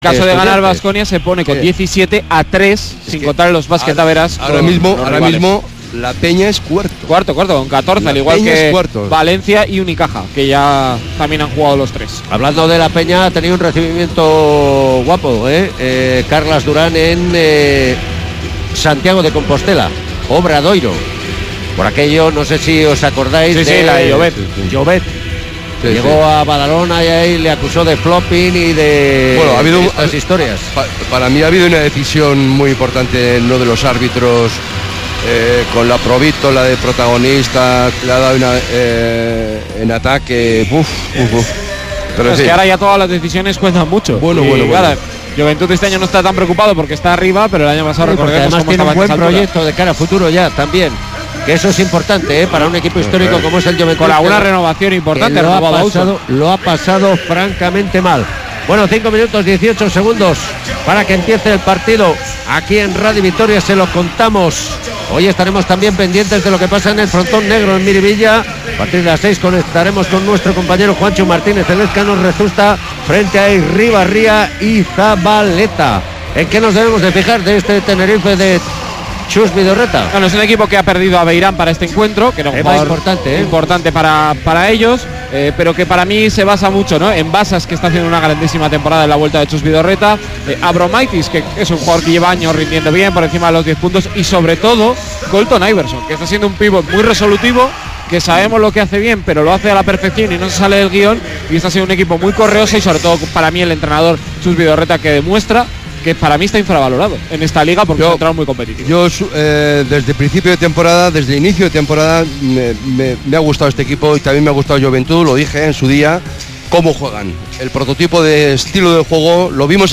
Kirolbet Baskonia-Iberostar Tenerife jornada 23 ACB 2018-19 retransmisión Radio Vitoria